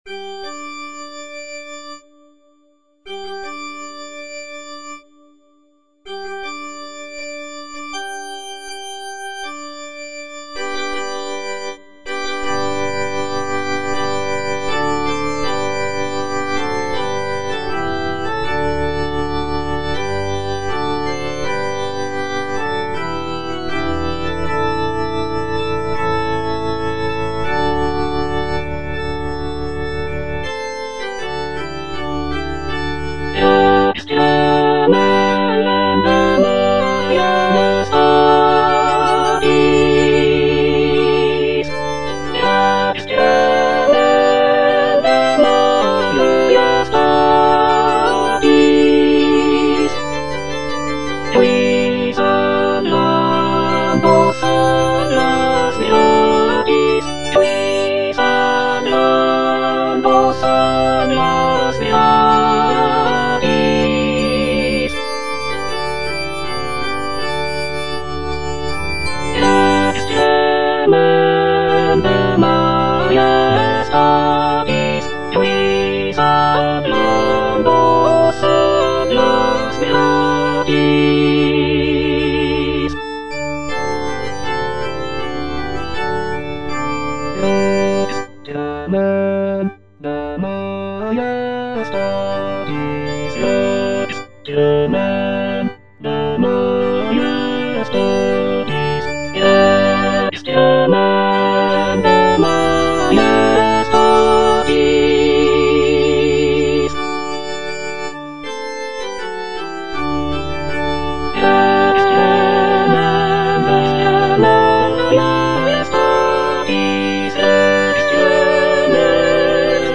(soprano II) (Emphasised voice and other voices) Ads stop
is a sacred choral work rooted in his Christian faith.